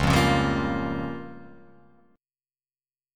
C#6b5 chord